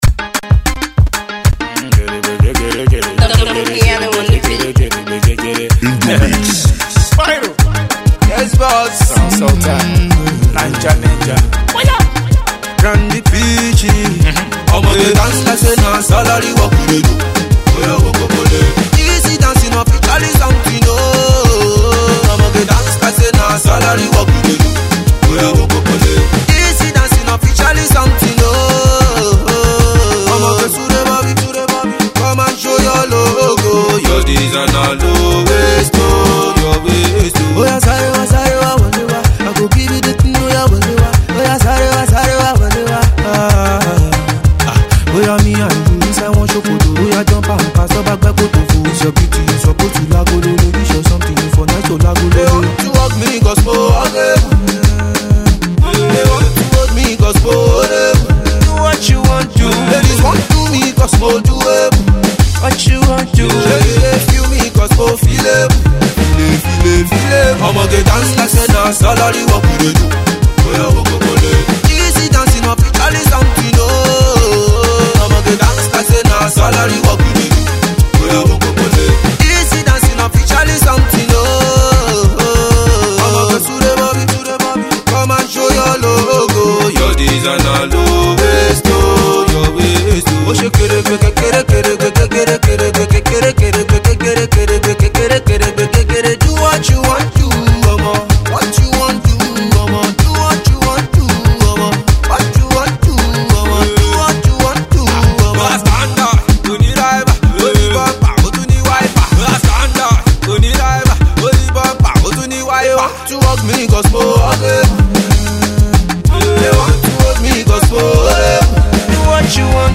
The talented crooner
with that local , infectious sound .